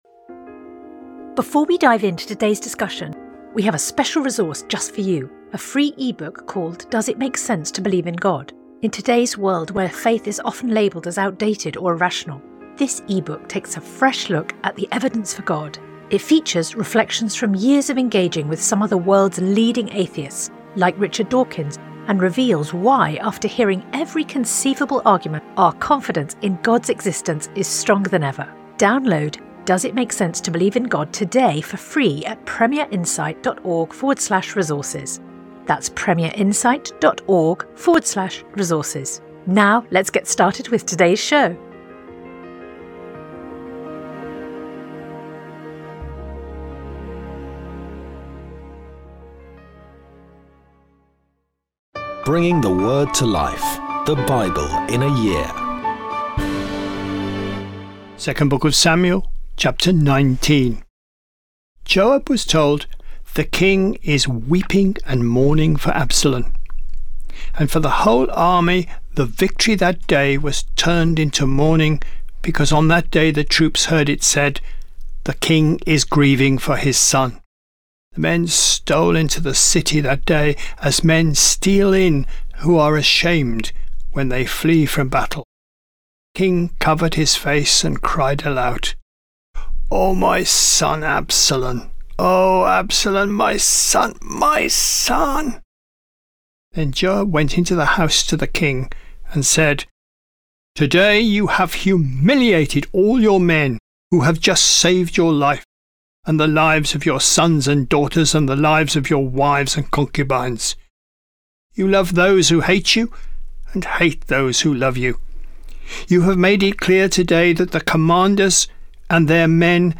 Today's reading comes from 2 Samuel 19; 3 John 1; Psalms 34